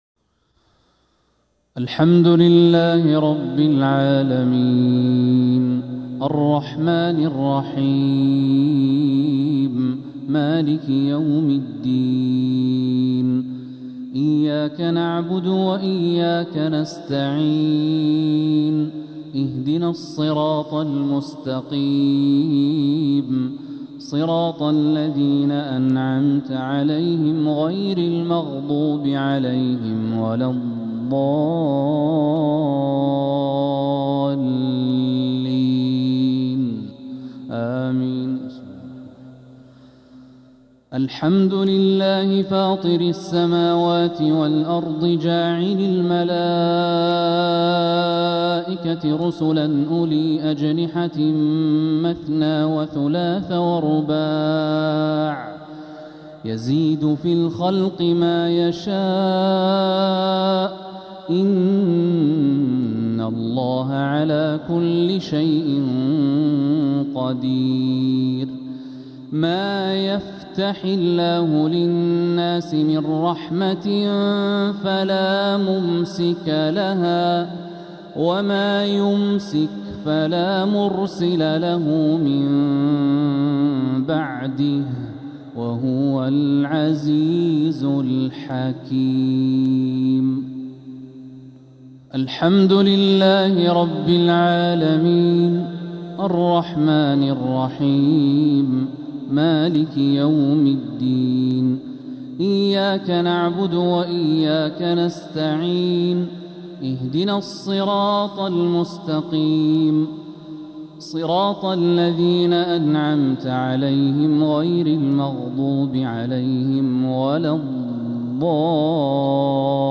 مغرب الأربعاء 7 محرم 1447هـ فواتح سورة فاطر 1-4 | Maghreb prayer from Surah Fatir 2-7-2025 > 1447 🕋 > الفروض - تلاوات الحرمين